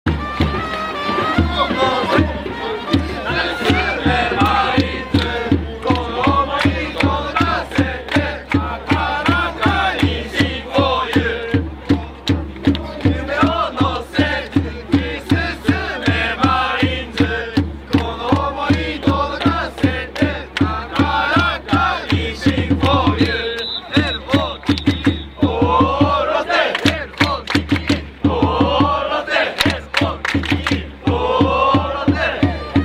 sound of stadium
このコーナーは管理人が隠れながら（？）球場で録音した音を公開していくコーナーです。